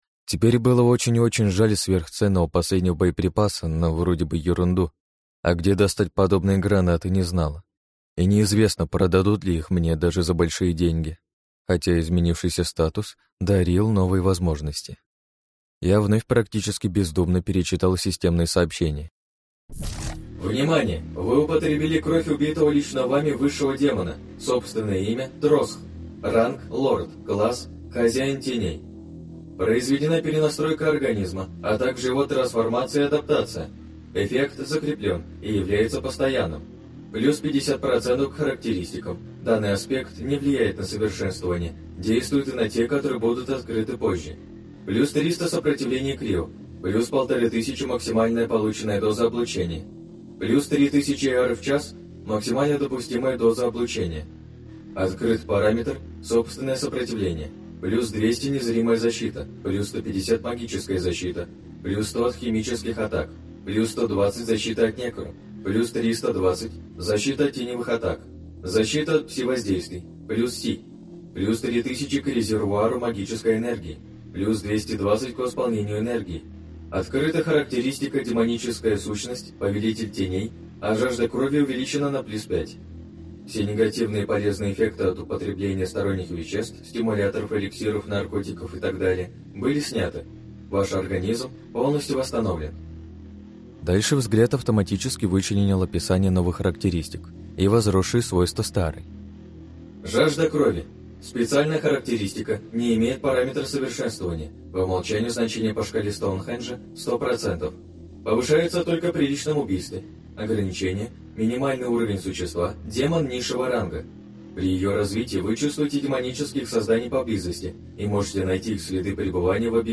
Аудиокнига Псы Нинеи. Стаф. Книга 4. Охотник за тенями | Библиотека аудиокниг